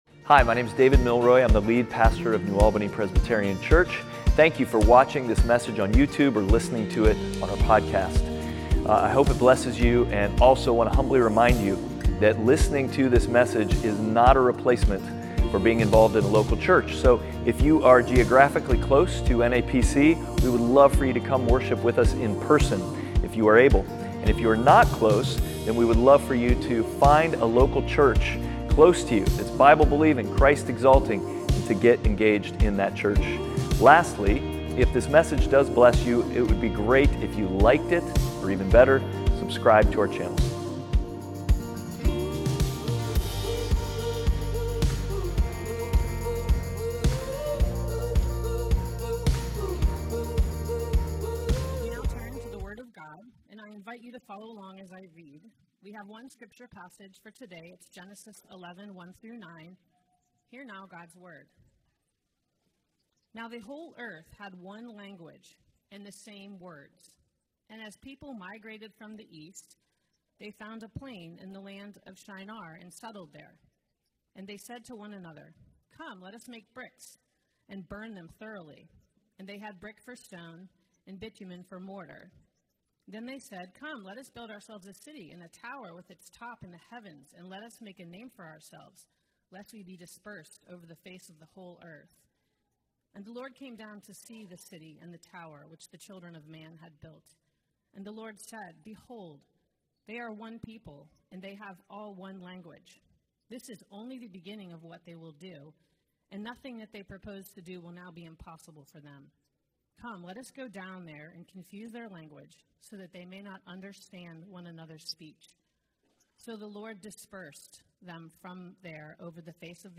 Service Type: Sunday Worship
NAPC_Sermon_6.22.25.mp3